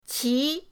qi2.mp3